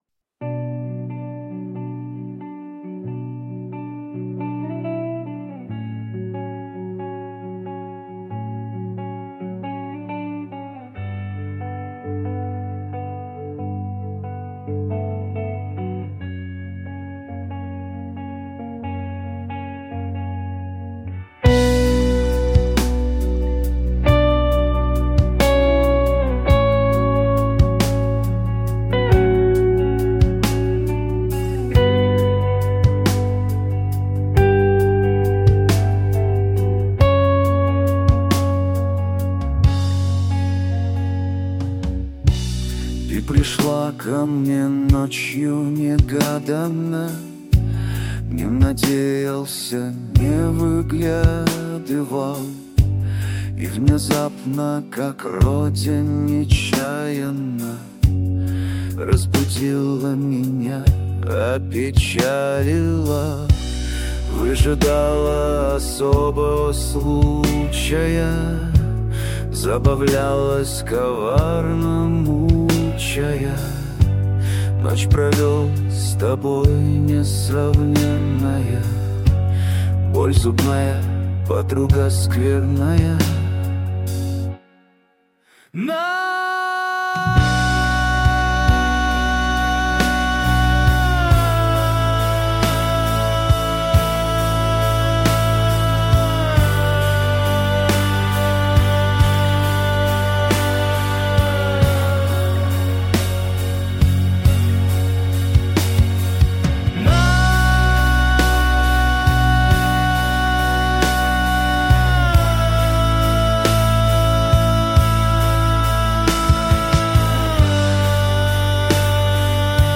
Текст автора, виконання штучне.
ТИП: Пісня
СТИЛЬОВІ ЖАНРИ: Драматичний